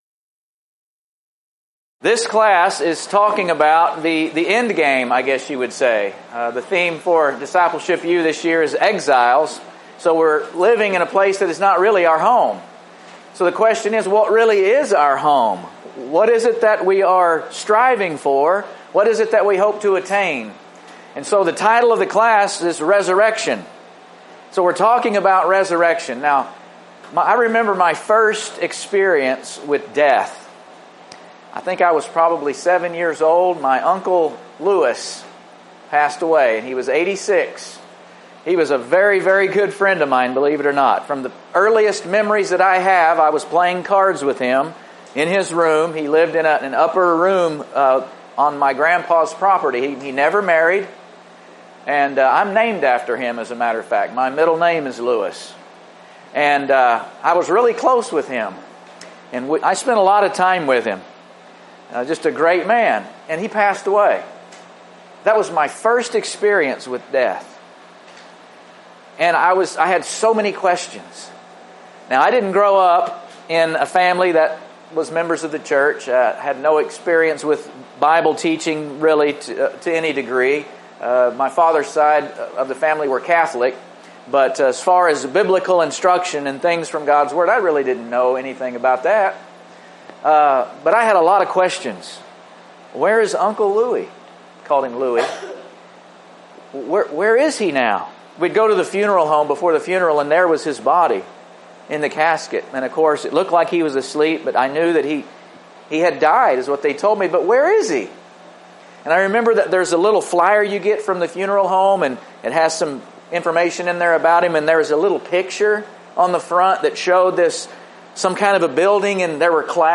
Event: 2017 Discipleship University Theme/Title: Exiles: Holy Faith in a Hostile World
lecture